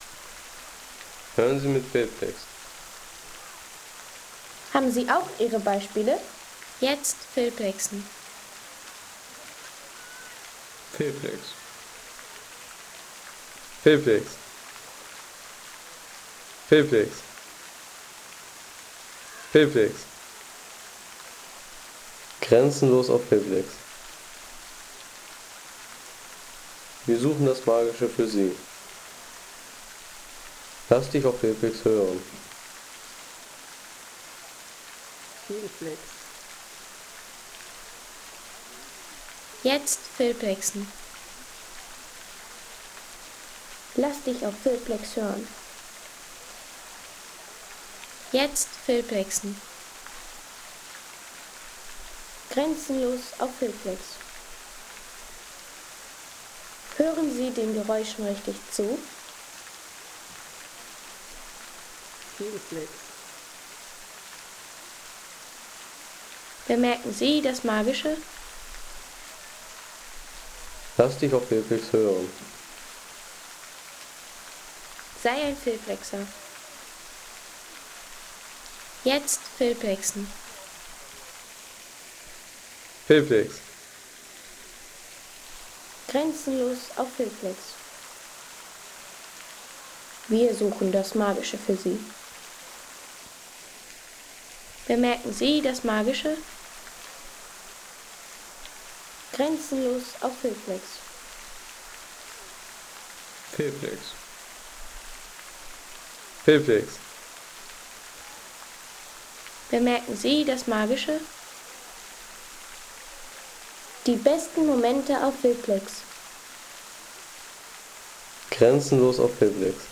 Fontana San Pietro – Der Brunnen auf dem Petersplatz.